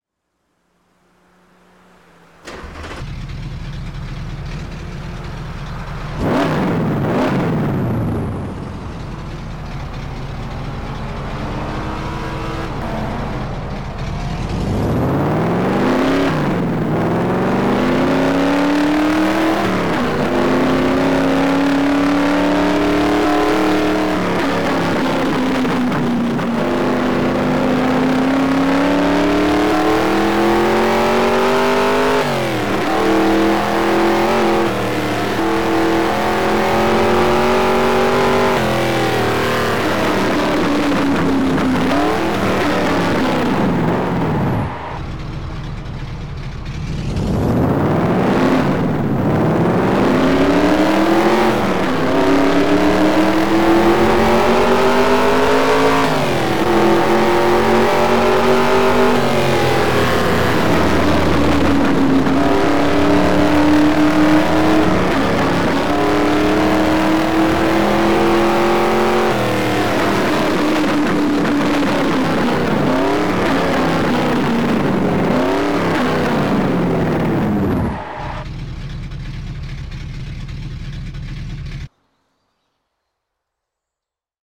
- Dodge Challenger R/T